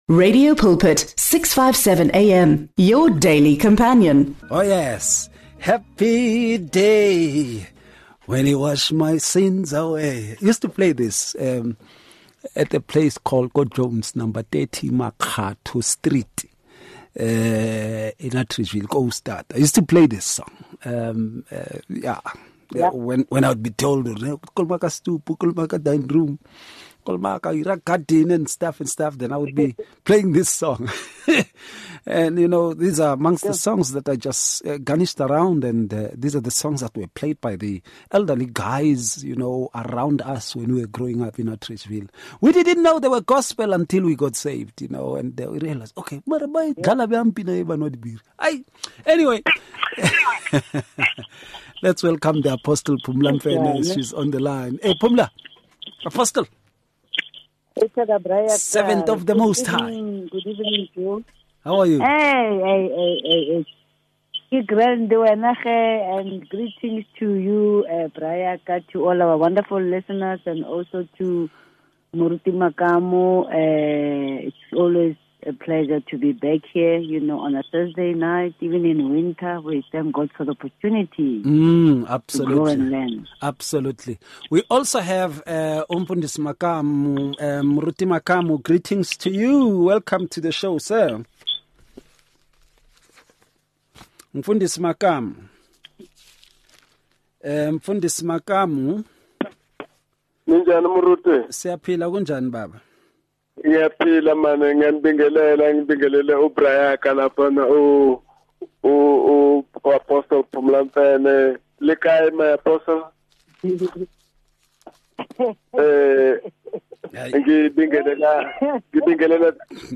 This dialogue aims to deepen understanding of spiritual perfection beyond worldly definitions.